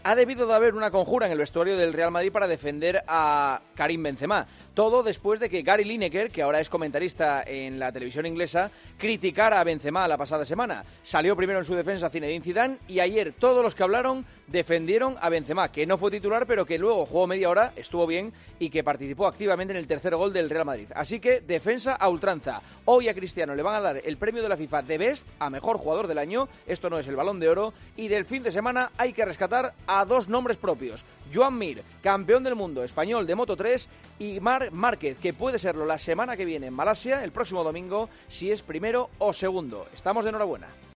El comentario de Juanma Castaño
La actualidad deportiva de este fin de semana en el comentario de Juanma Castaño en 'Herrera en COPE'.